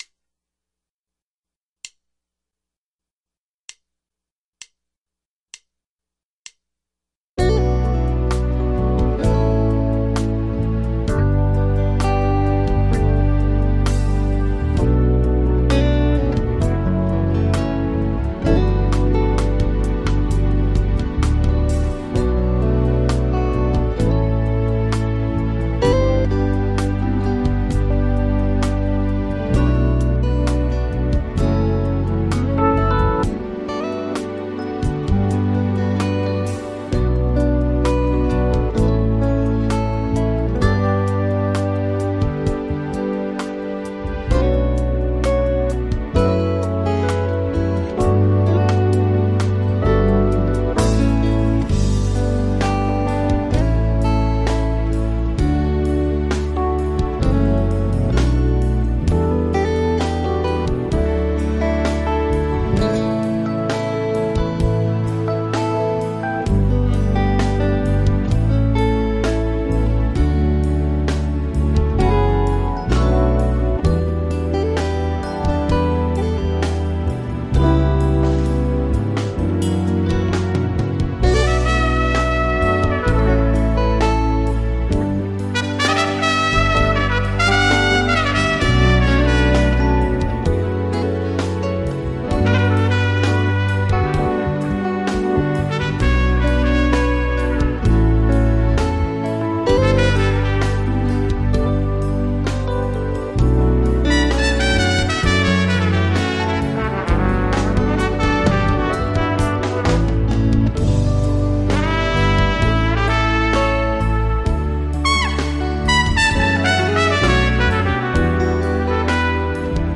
Base e 1 solista (trompeta)